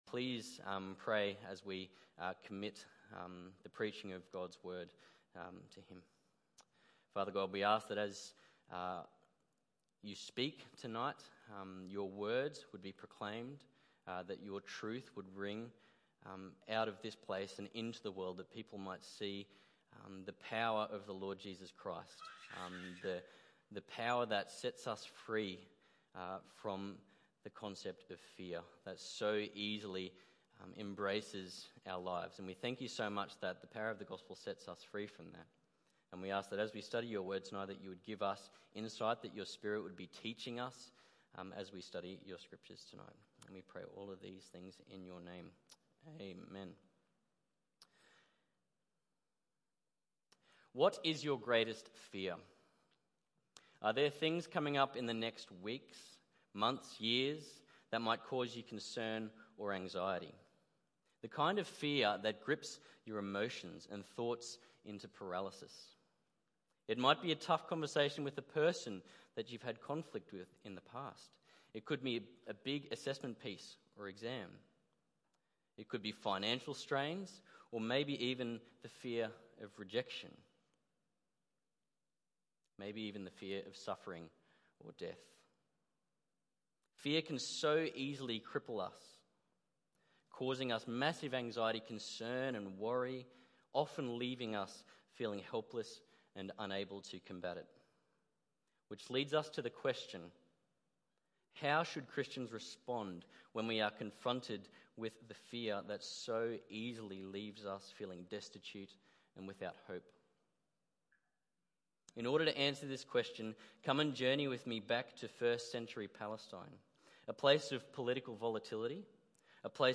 Guest Speaker
However, the book of Acts reminds us that God will grow his Church in spite of opposition to His Gospel message. The true hope that the Church has is found in Jesus’ promise that He will grow His Church and the gates of Hell will not prevail against it. Acts 12:1-25 Tagged with Sunday Evening